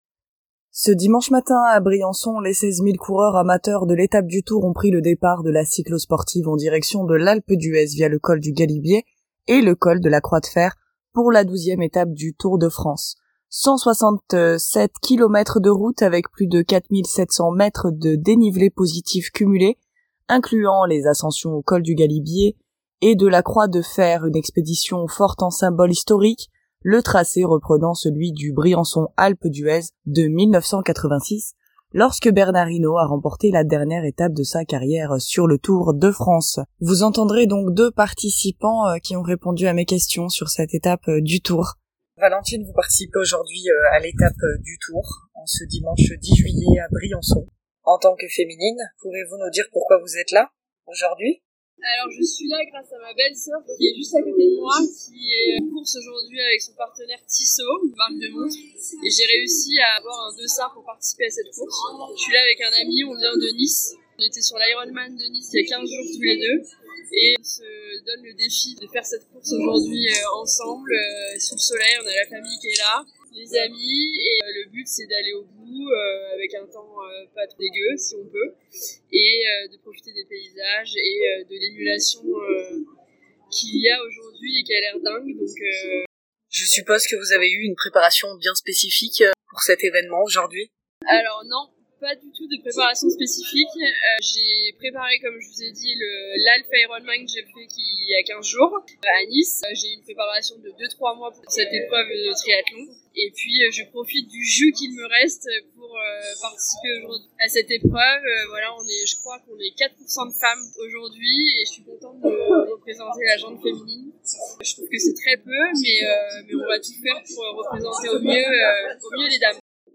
Vous entendrez 2 participants